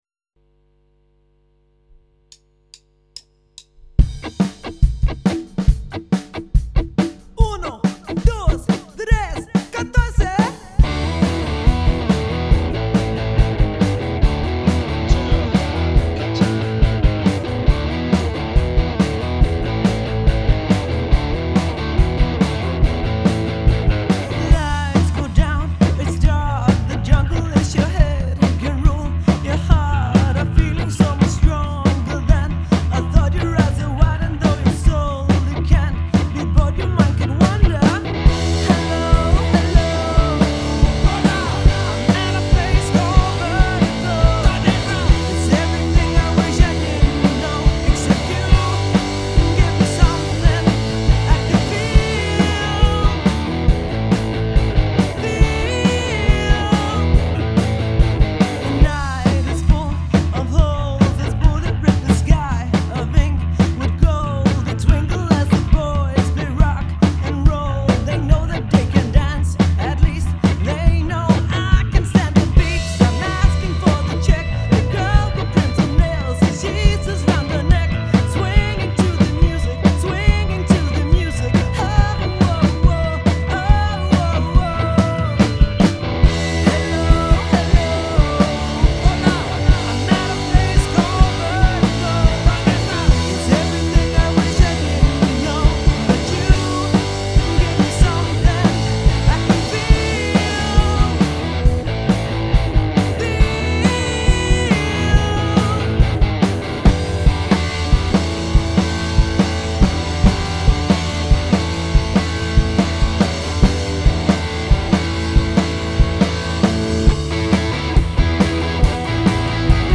Svedende dansegulv, klassiske guitarriffs og fællessang
• Coverband
• Rockband